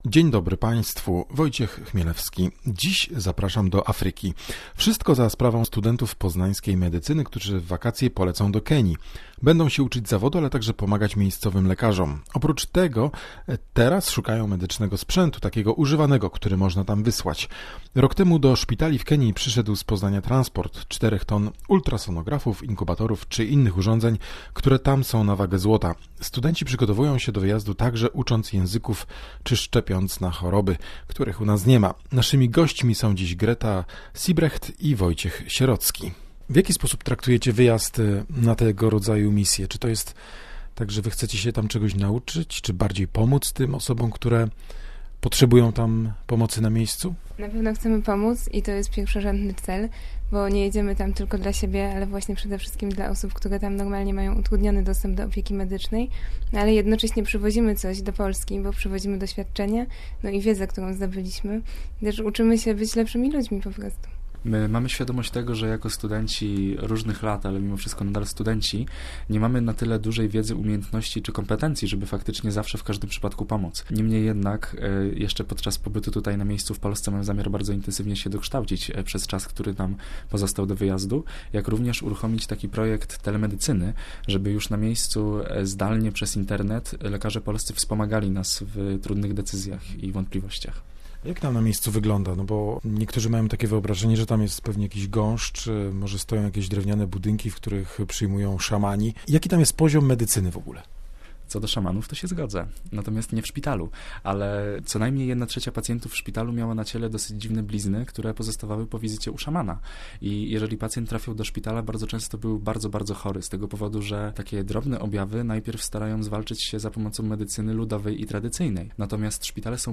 Rozmowa ze studentami medycyny na temat projektu "Leczymy z misją".